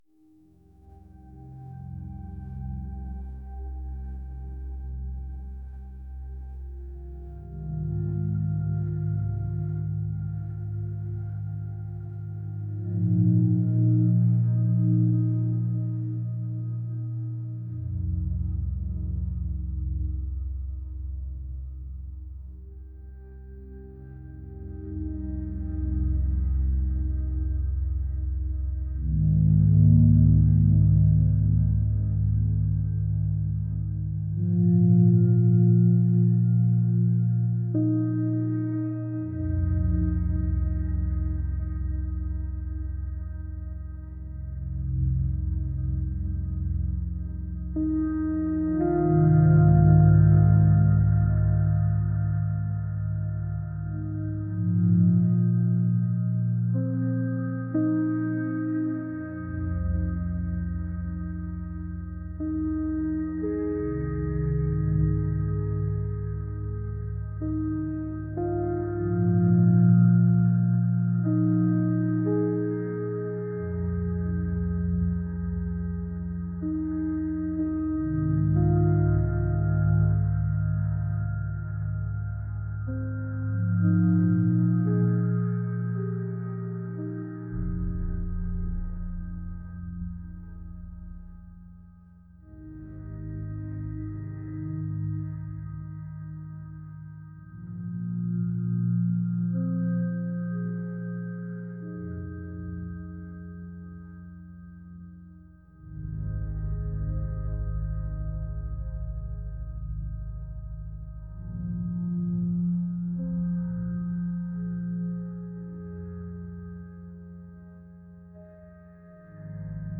ambient | ethereal